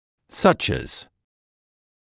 [sʌʧ æz][сач эз]такой как, такие как